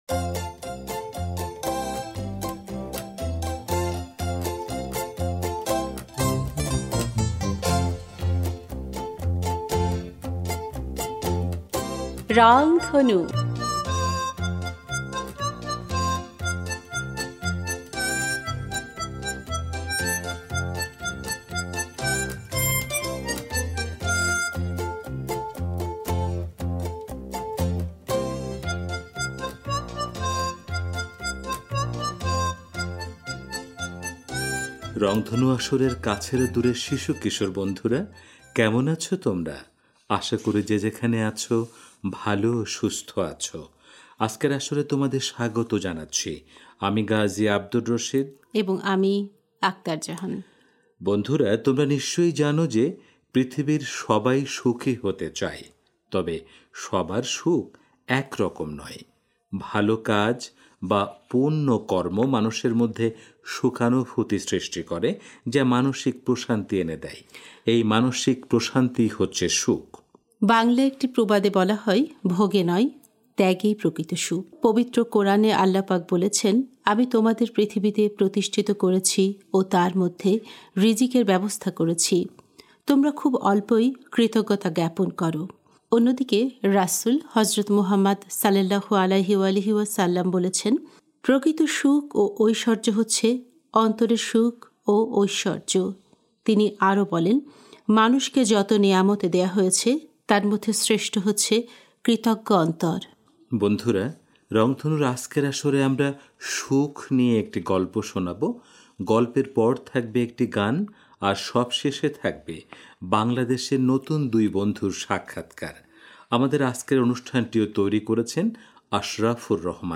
বন্ধুরা, রংধনুর আজকের আসরে থাকছে সুখ নিয়ে একটি গল্প। গল্পের পর থাকবে একটি গান।